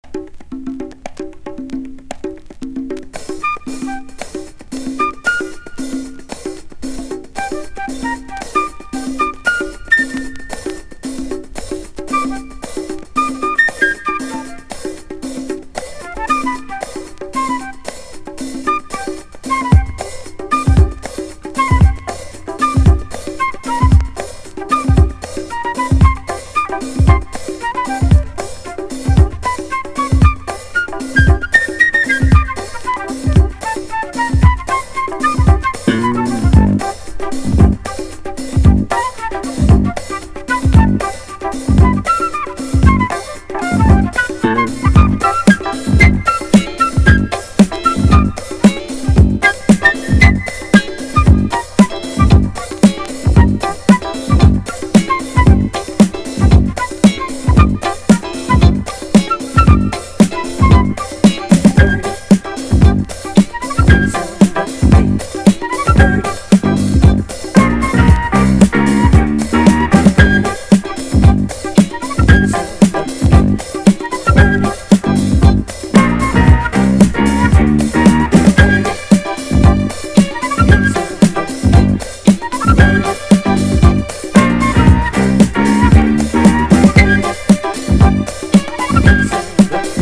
軽快なグルーヴの長尺ジャズ・ファンク～ディスコ
JAZZ FUNK / SOUL JAZZ# FUNK / DEEP FUNK# DISCO